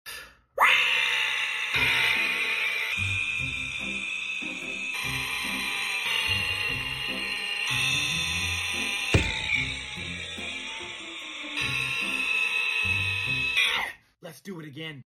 The longest REEEE ever PT2 sound effects free download